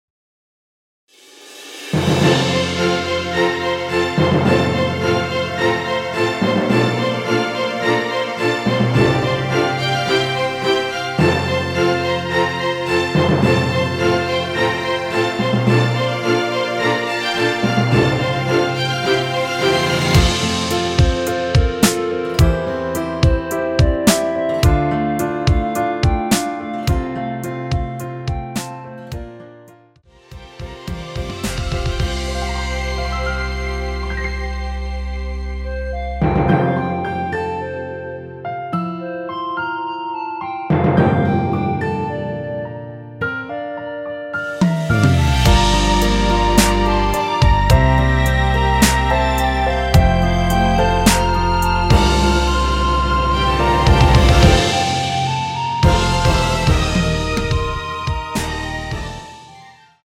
원키에서(+5)올린 멜로디 포함된 MR입니다.(미리듣기 확인)
앞부분30초, 뒷부분30초씩 편집해서 올려 드리고 있습니다.
(멜로디 MR)은 가이드 멜로디가 포함된 MR 입니다.